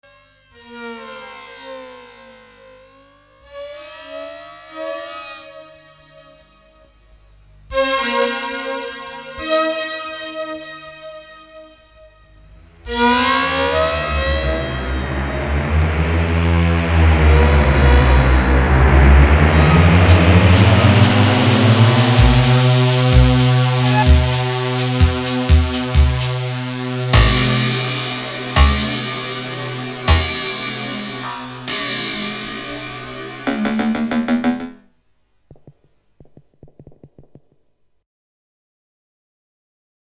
Genres: eesti , experimental , house